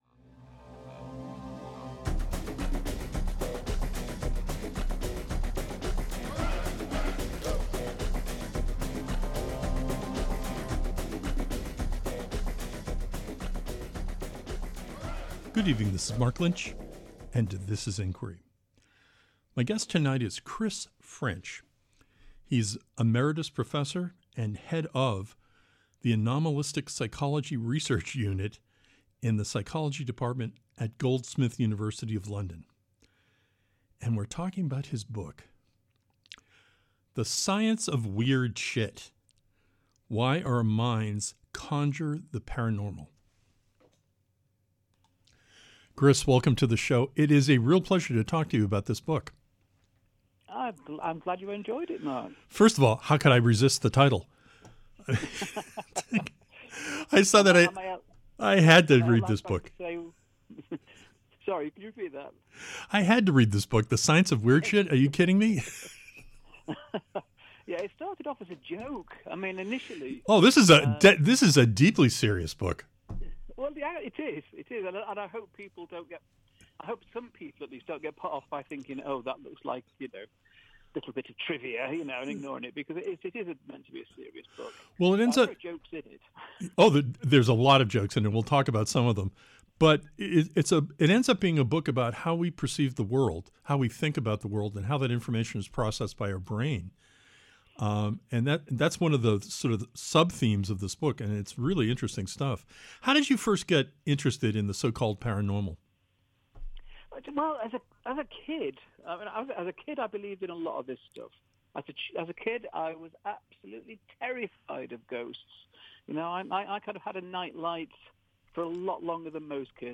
Is there a scientific pathway to analyze these extraordinary claims? On this episode of Inquiry, we talk with CHRIS FRENCH.